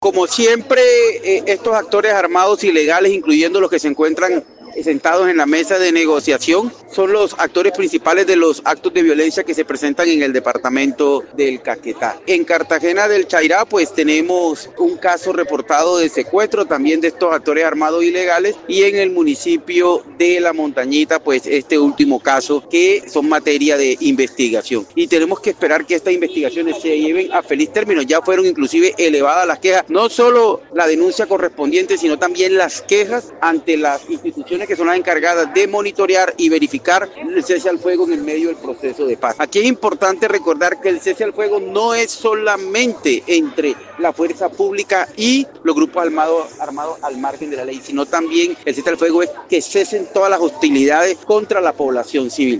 Gobernador del Caquetá pide “no mas secuestros”.